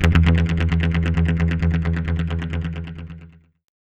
GUITARFX 5-R.wav